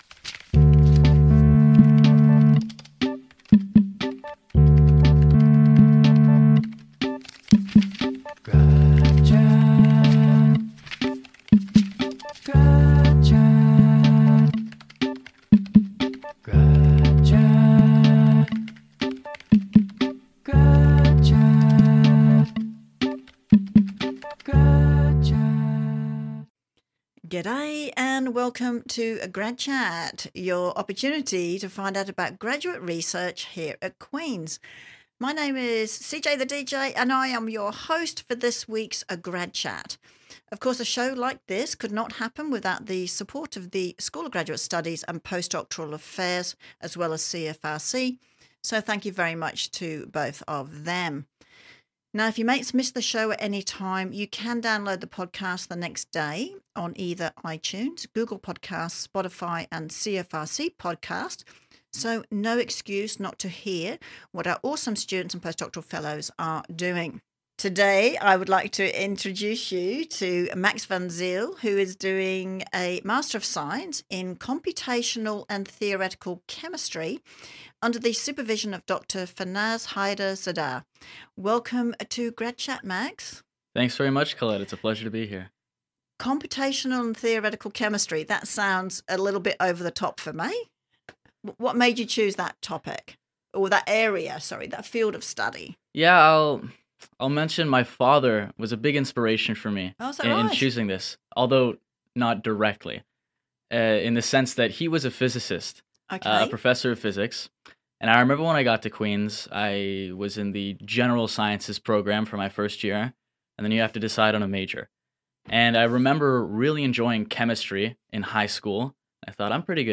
This interviews highlights the work of Dr Bader and how Queen’s will showcase his love for chemistry and the arts.